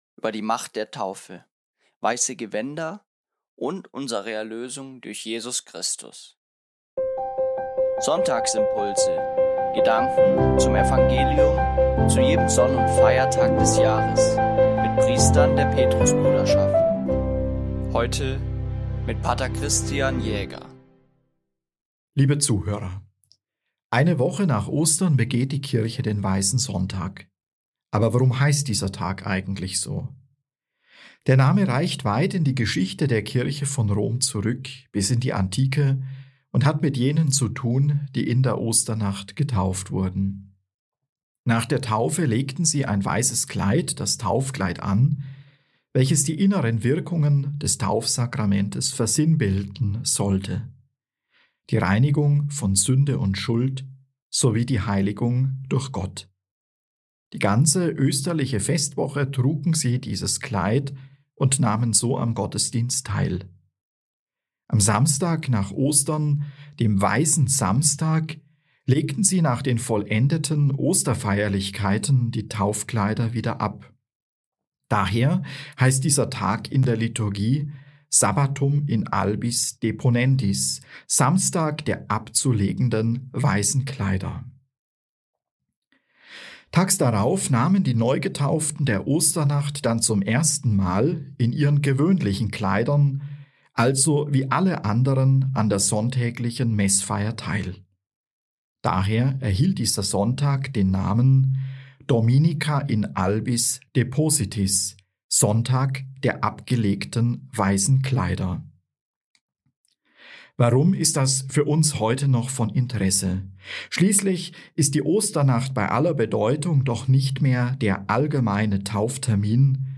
Gedanken zum Evangelium – für jeden Sonn- und Feiertag des Jahres mit Priestern der Petrusbruderschaft